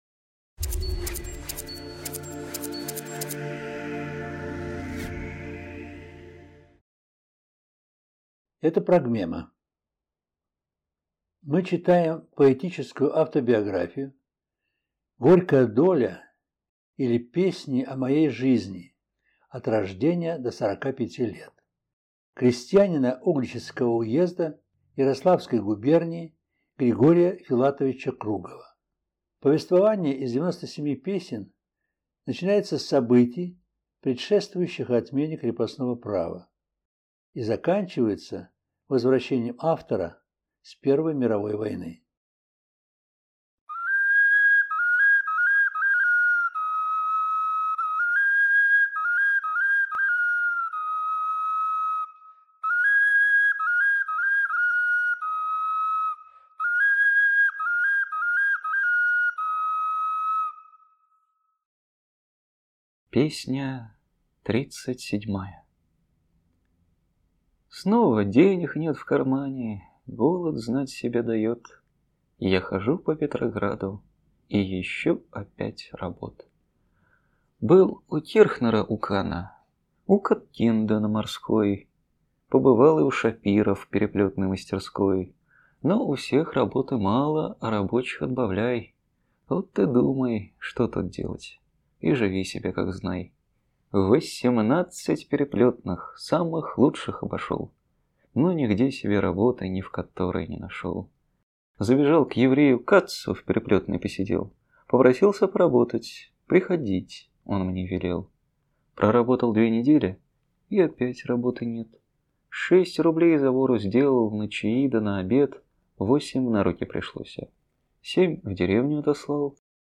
Это лекция №35 из курса Гражданского права (общей части).